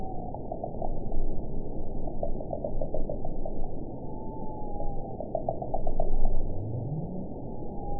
event 922140 date 12/27/24 time 06:54:28 GMT (4 months, 1 week ago) score 9.11 location TSS-AB06 detected by nrw target species NRW annotations +NRW Spectrogram: Frequency (kHz) vs. Time (s) audio not available .wav